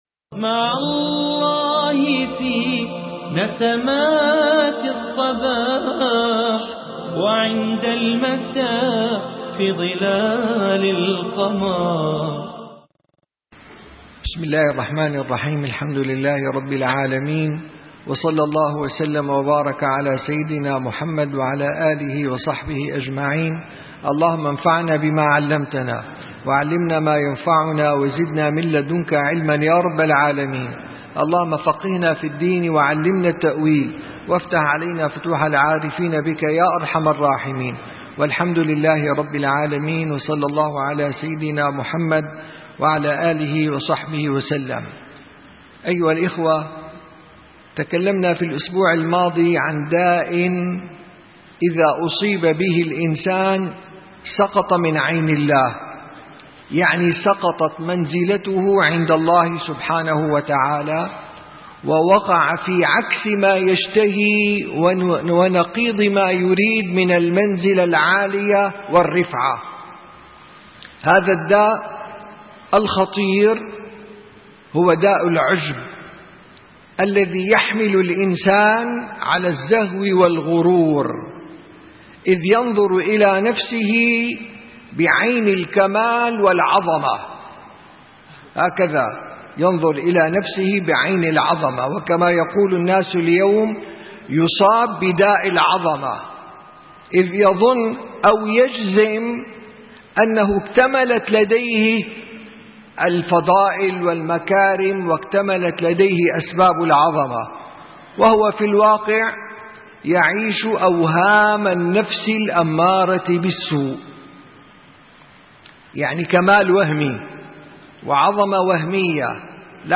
3 - درس جلسة الصفا: خطر العجب 2